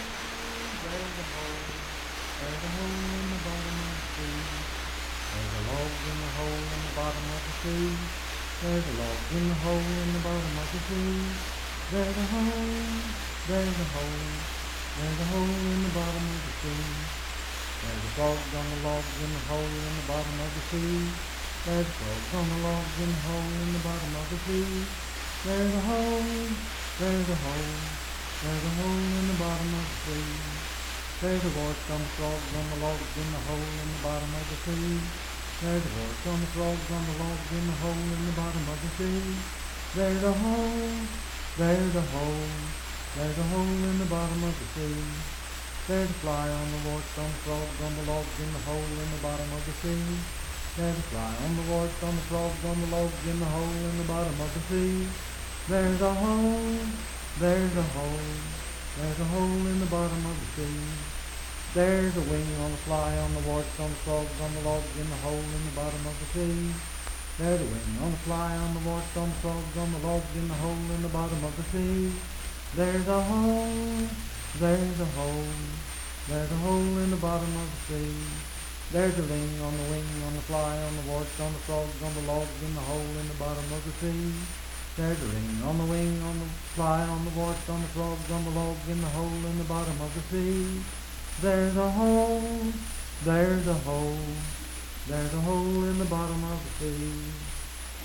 Unaccompanied vocal music
Dance, Game, and Party Songs
Voice (sung)